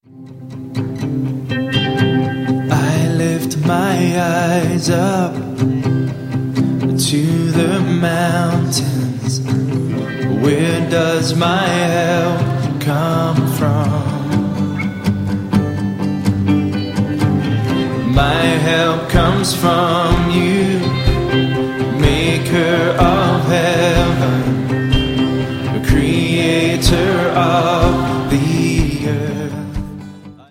25 modern worship favorites
• Sachgebiet: Praise & Worship